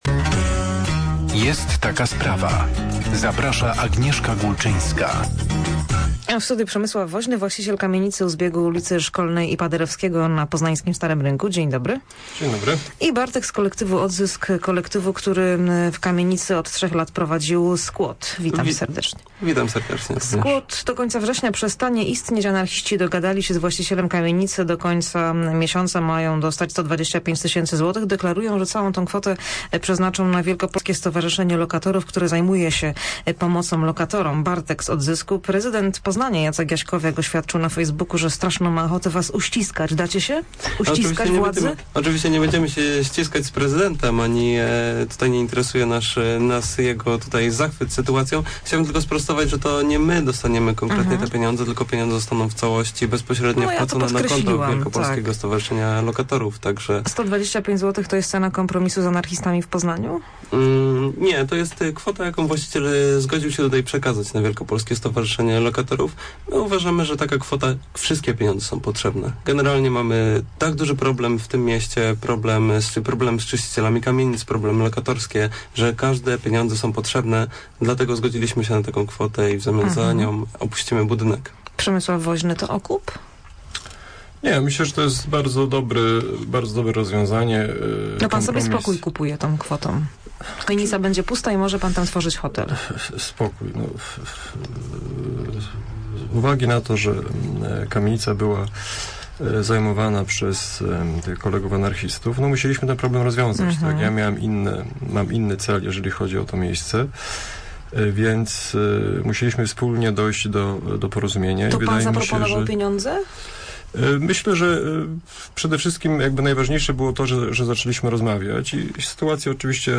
89onr6lw6xe9irp_odzysk_rozmowa.mp3